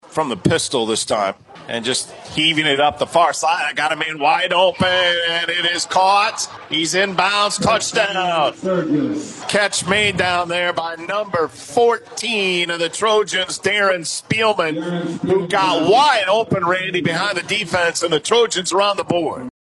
(audio courtesy of Fox Sports Radio 99.7 FM/1230 AM)